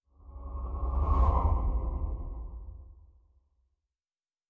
Distant Ship Pass By 5_4.wav